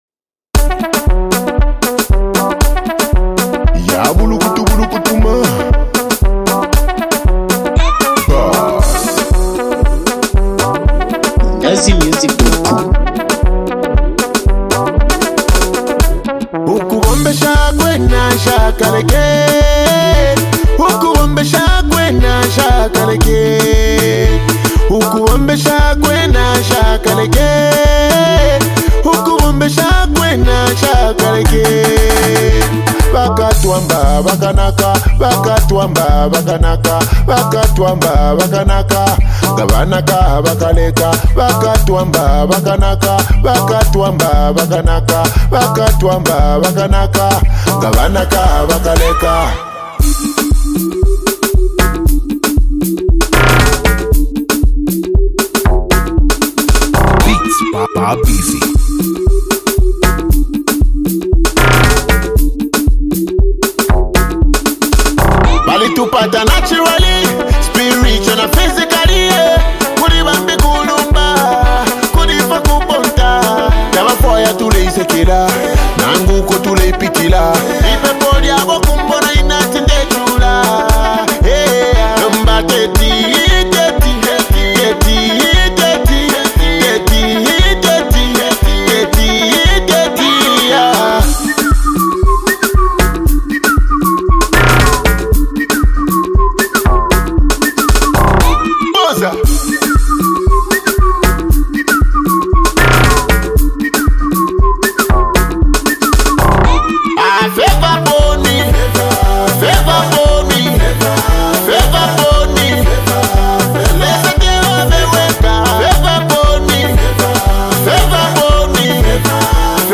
dance song